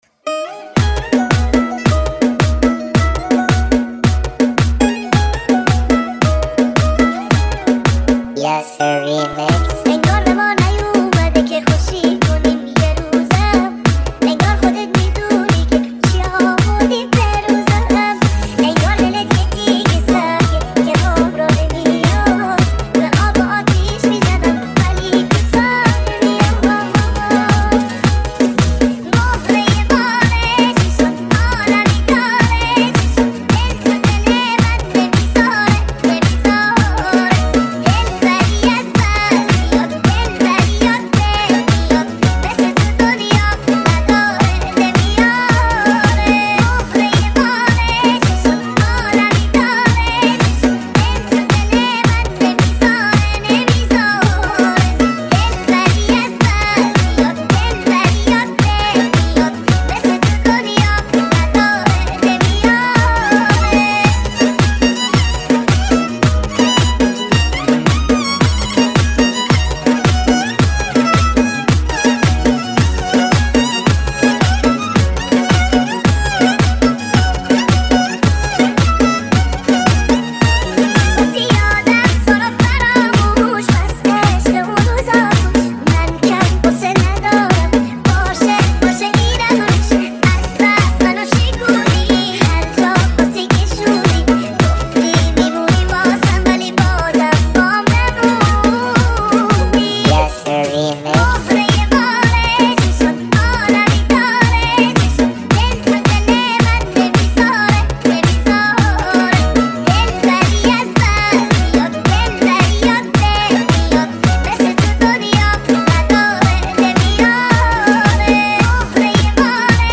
آهنگ گفتی یادم تورو فراموش ریمیکس با دو حالت ۳۲۰ و ۱۲۸ از
متن آهنگ گفتی یادم تو رو فراموش صدای بچه